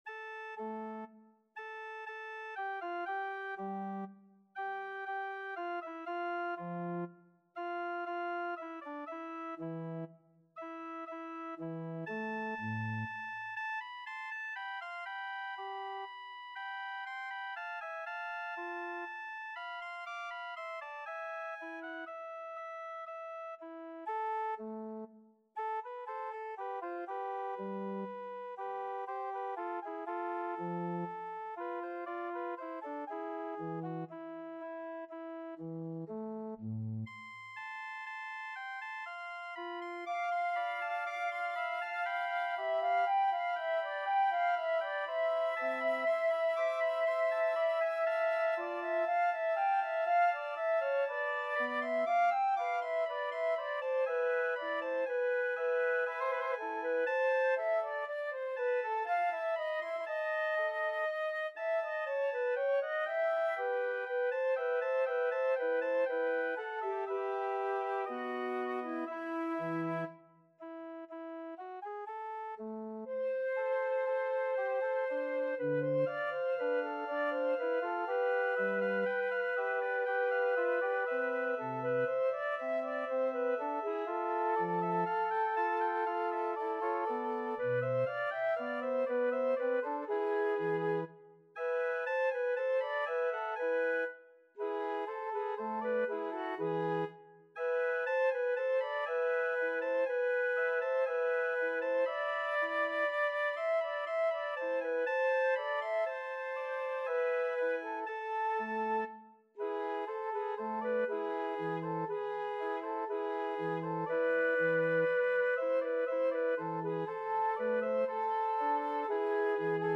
3/4 (View more 3/4 Music)
E5-D7
Classical (View more Classical Flute Music)